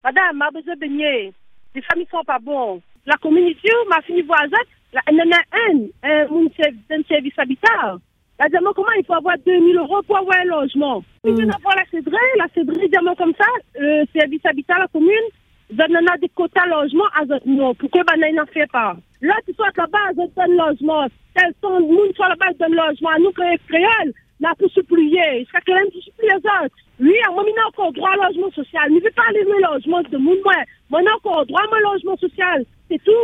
À Saint-Paul, une habitante lance aujourd’hui un appel au secours. Depuis deux ans, elle cherche un logement sans succès.